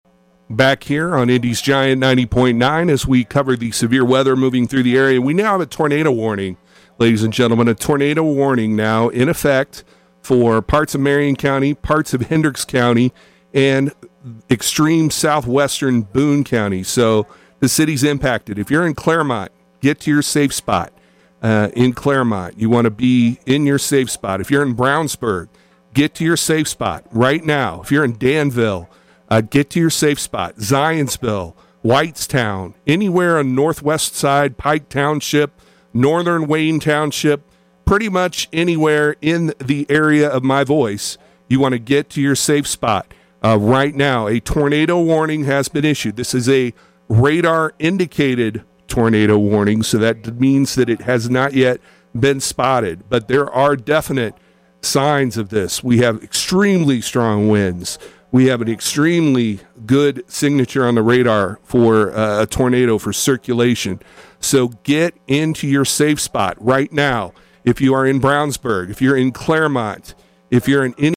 WBDG was on the air live pushing out severe weather coverage when a large pine tree along Girls School Road was blown down wiping out power to the station and blocking the road. Here's how it sounded on air.